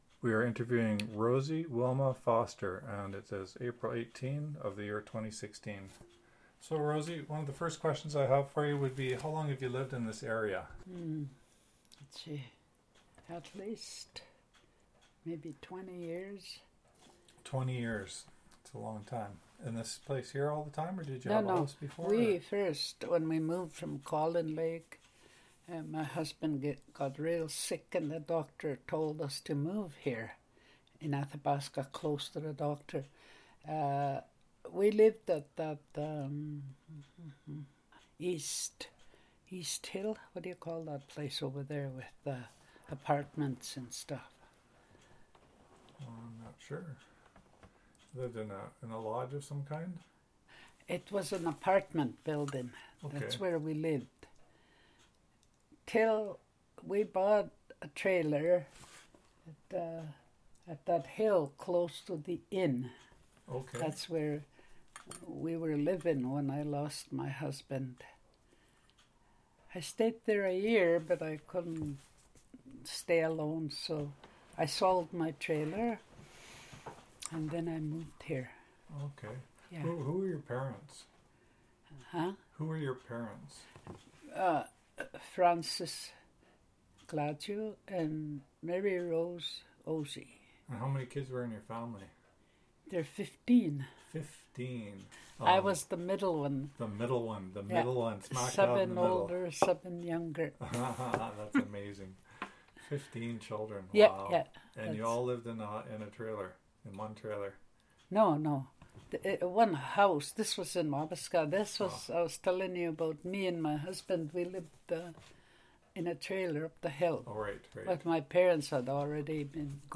Audio interview and transcript of audio interview,